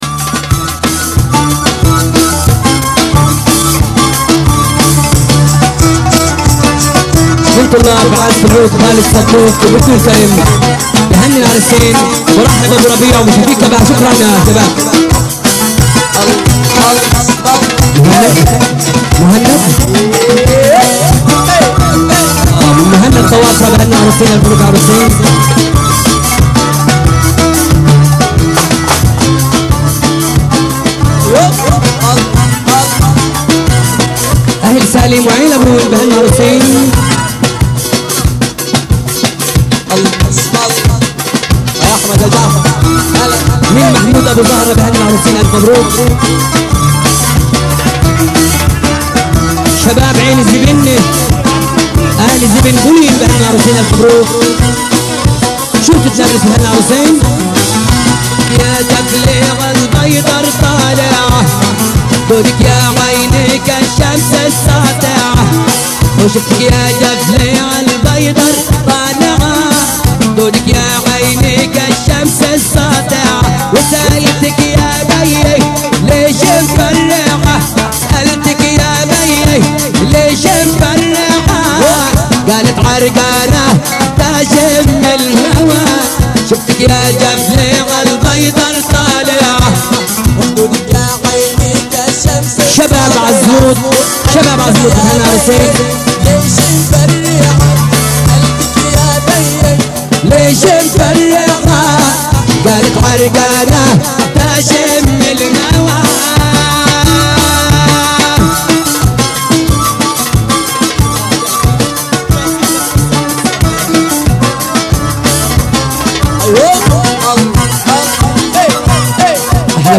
أغاني فلسطينيه